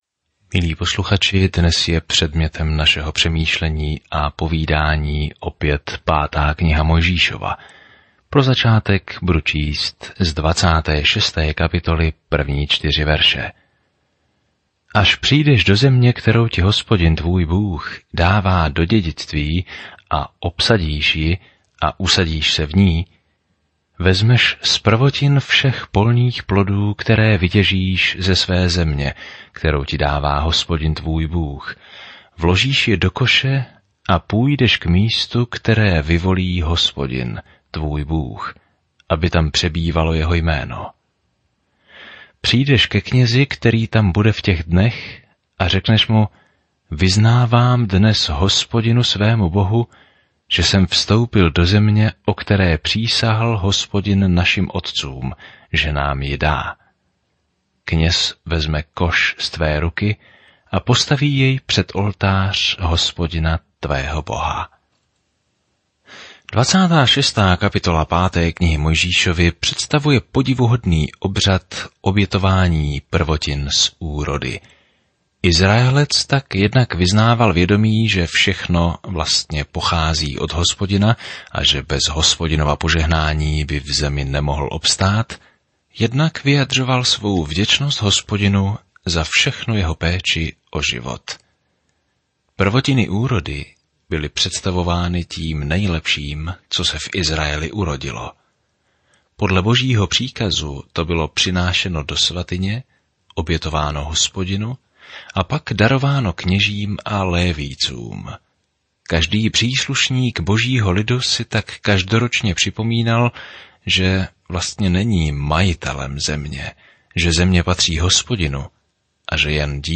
Písmo Deuteronomium 26 Deuteronomium 27 Deuteronomium 28:1-12 Den 22 Začít tento plán Den 24 O tomto plánu Deuteronomium shrnuje dobrý Boží zákon a učí, že poslušnost je naší odpovědí na jeho lásku. Denně procházejte Deuteronomium a poslouchejte audiostudii a čtěte vybrané verše z Božího slova.